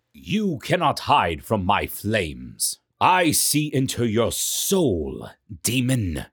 Video Game Character Voice Design
Middle Aged